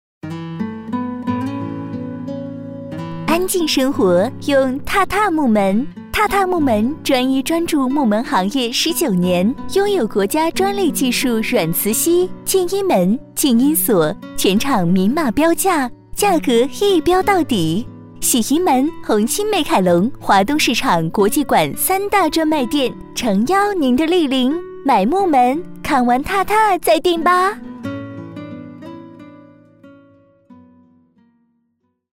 10 女国122_广告_家居家电_TATA木门_甜美 女国122
女国122_广告_家居家电_TATA木门_甜美.mp3